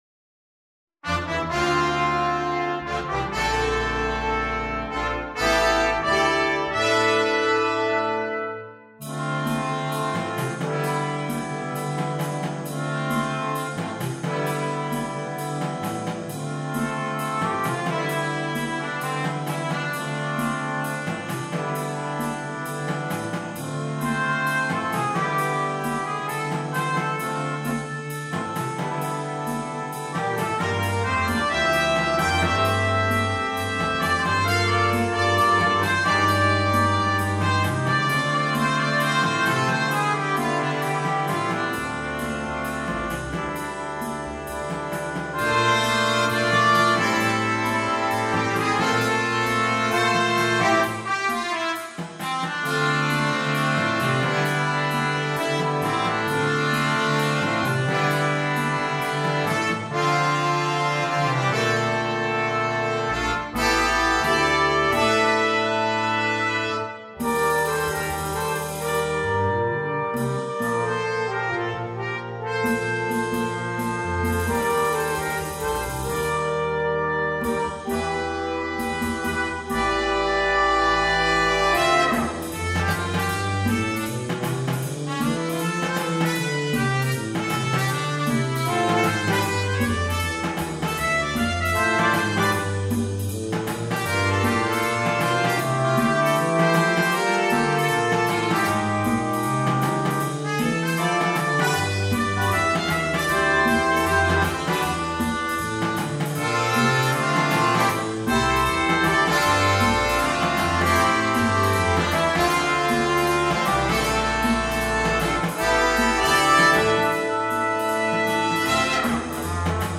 Brass Quintet (optional Drum Set)
There is an optional drum set part provided.